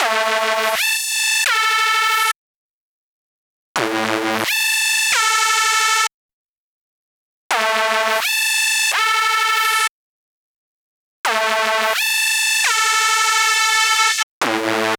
Session 11 - Lead 01.wav